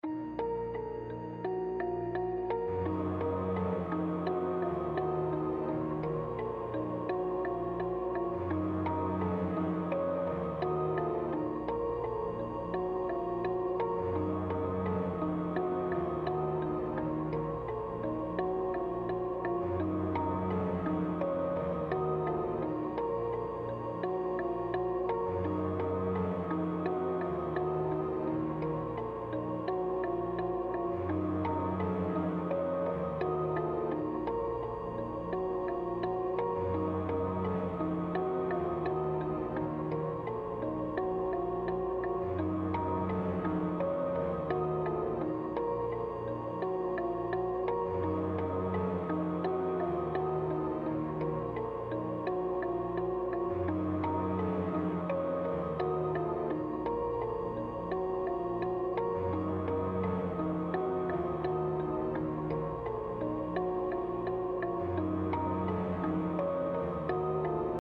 5.dark-synth.mp3